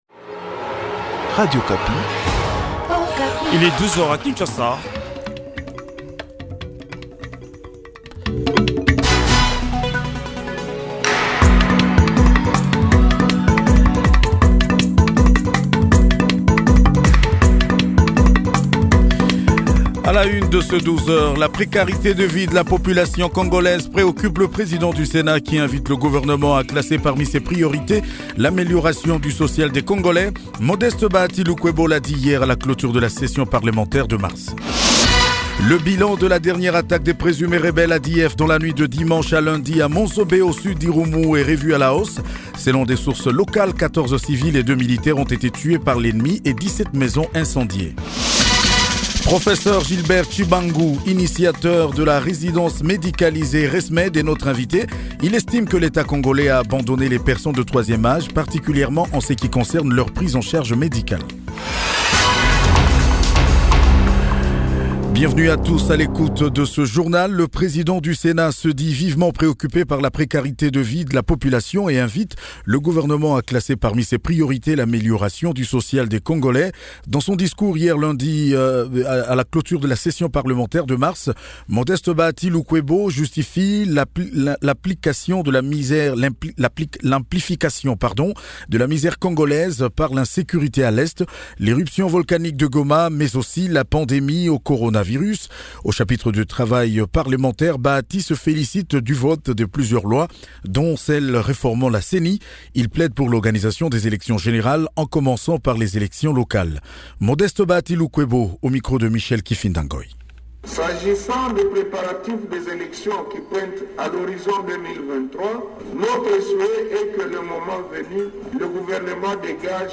JOURNAL MIDI DU 29 JUIN 2021